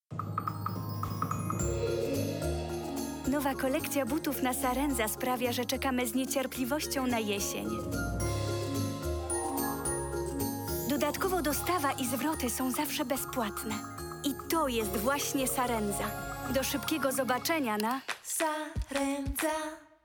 Comédienne voix-off franco-polonaise
Sprechprobe: Werbung (Muttersprache):